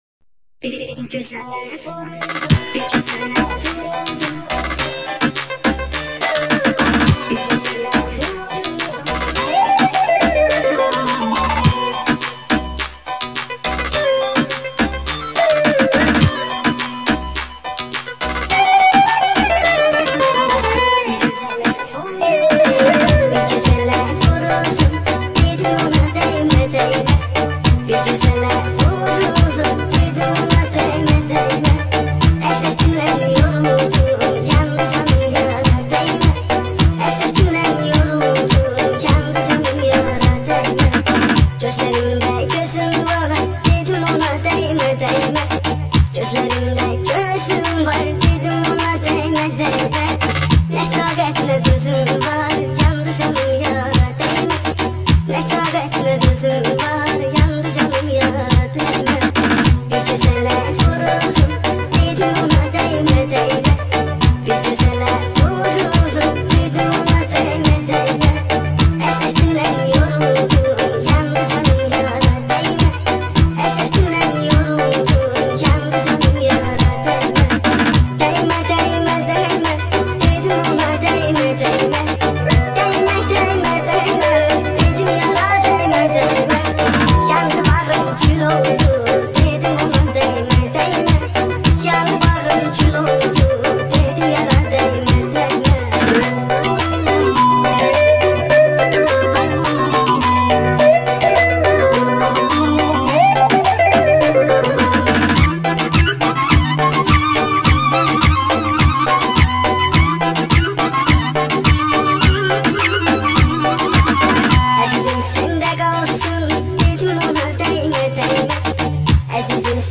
ریمیکس اینستا تند بیس دار سیستمی